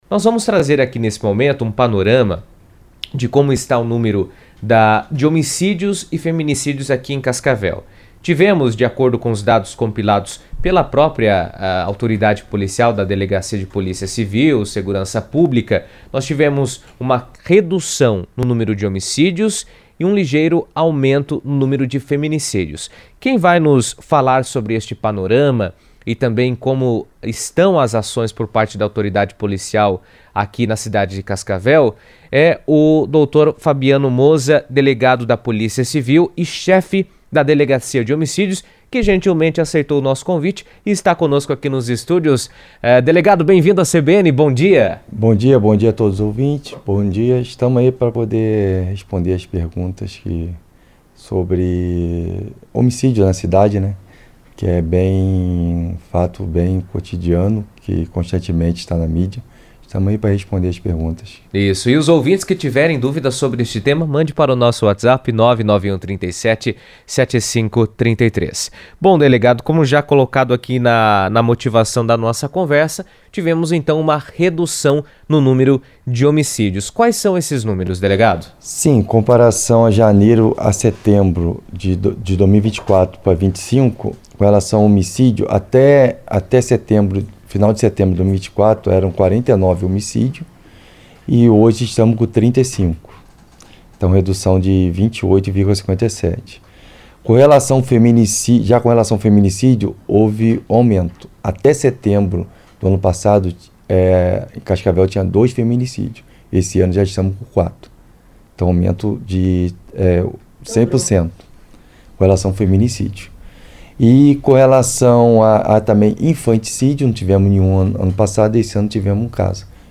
Cascavel registrou queda no número de homicídios no primeiro semestre deste ano, indicando avanços nas ações de segurança pública na cidade. Em entrevista à CBN